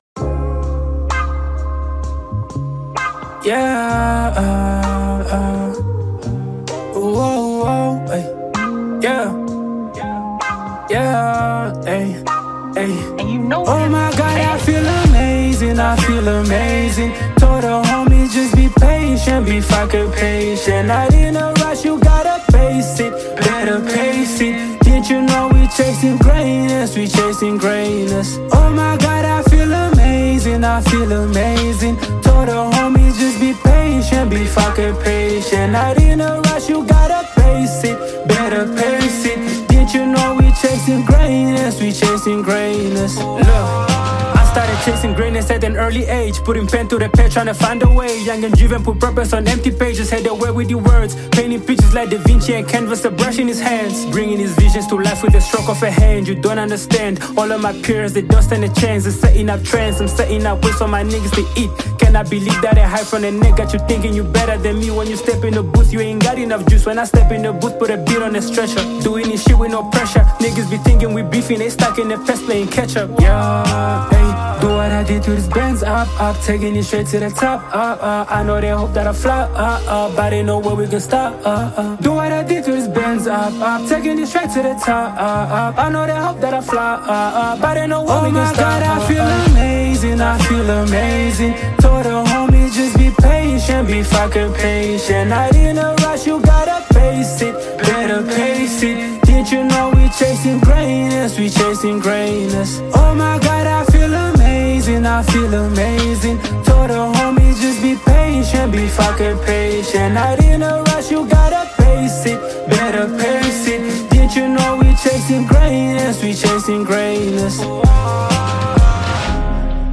Hip-hopMusic